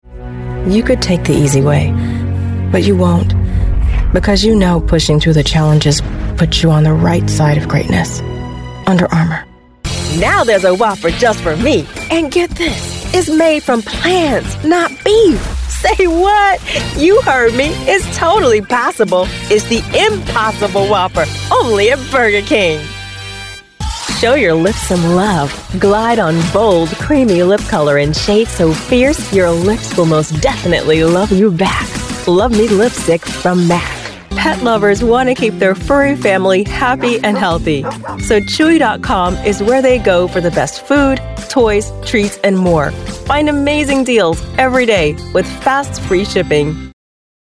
Warm, sharp and strong